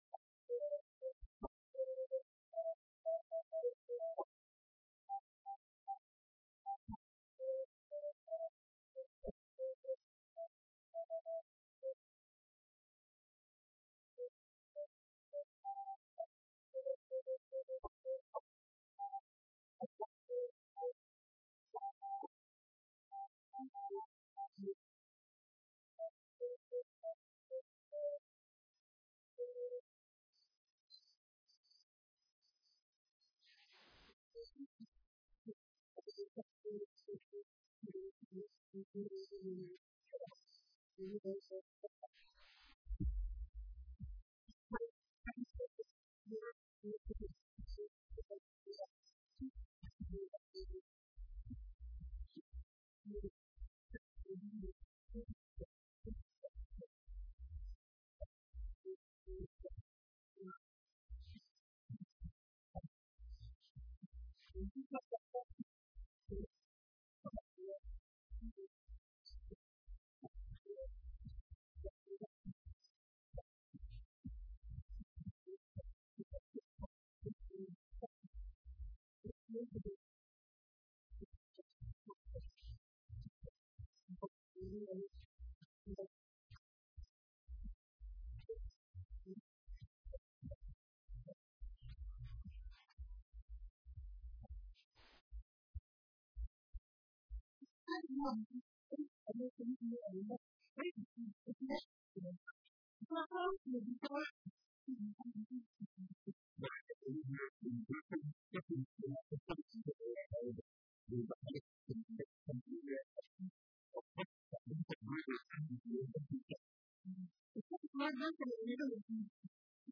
Dhageyso: Warka Subax ee Radio Muqdisho